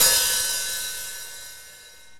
OPEN HAT.wav